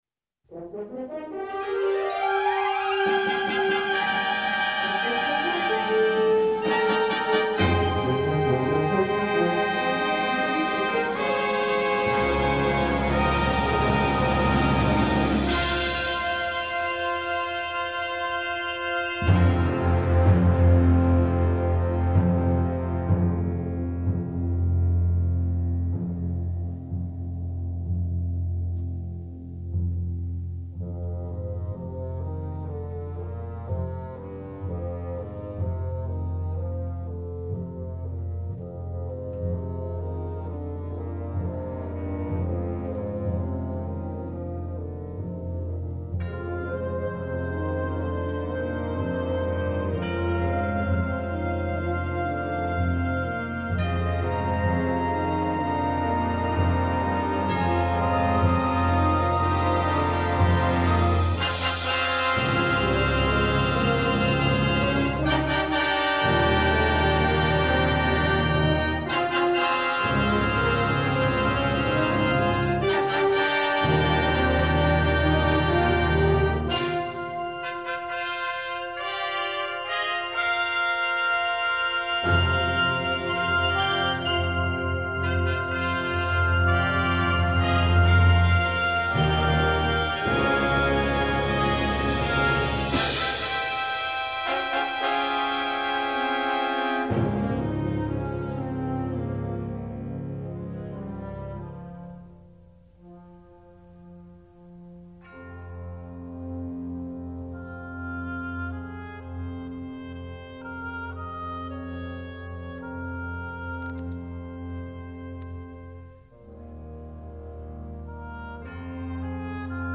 Plainsong